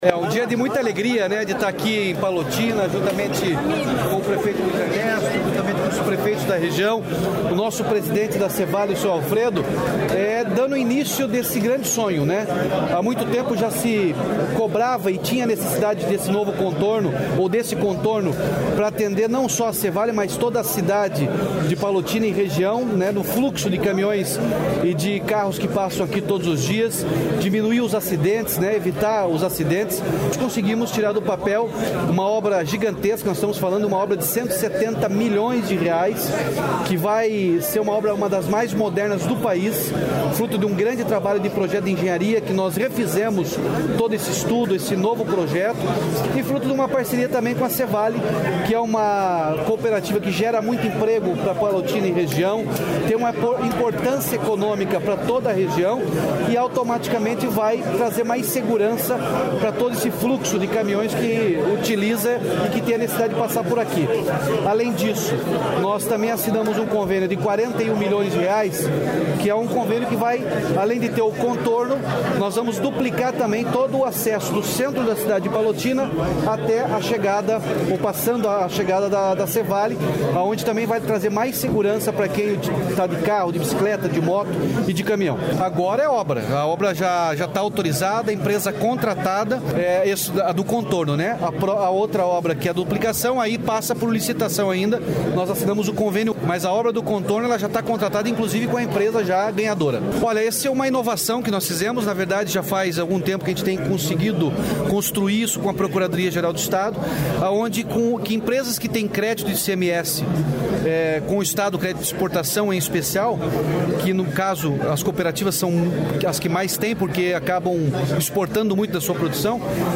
Sonora do governador Ratinho Junior sobre as obras em Palotina